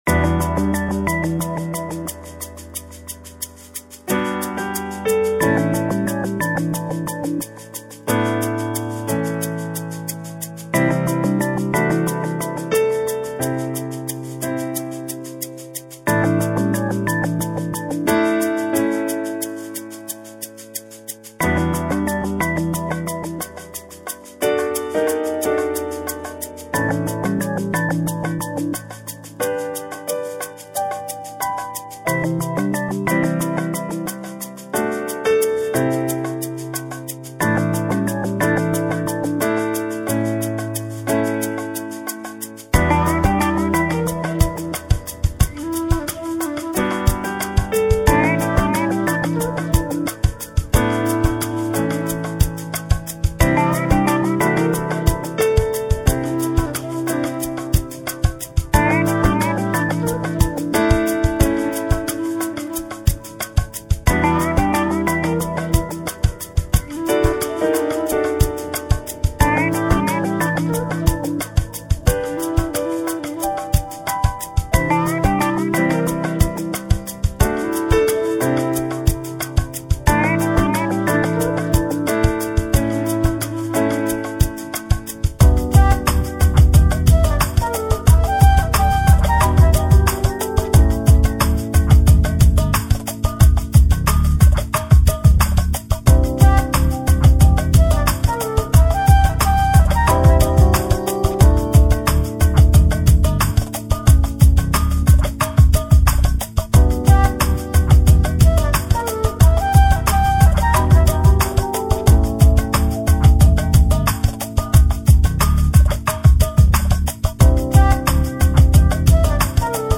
LIVE - AMBIENT - CHILLOUT - ATHMOAPHERIC
chillout- und loungemusik
cool & tanzbar - relaxed & groovig - dezent & frisch